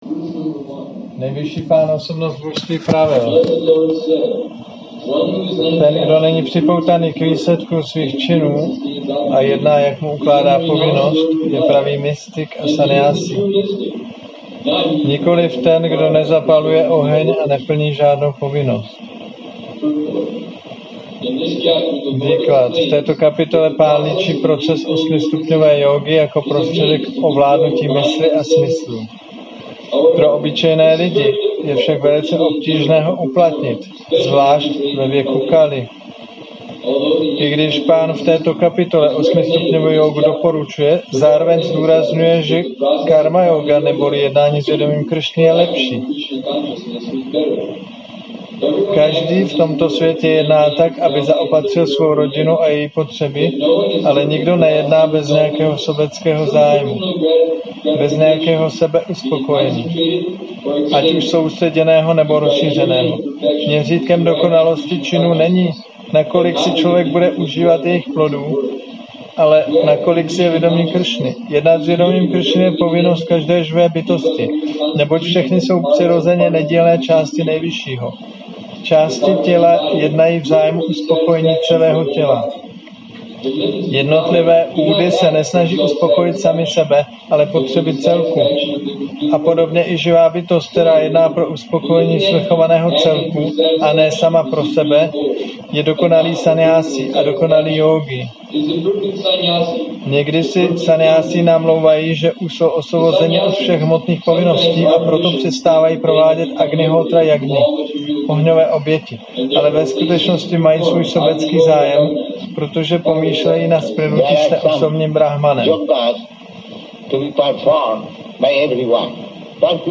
Přednáška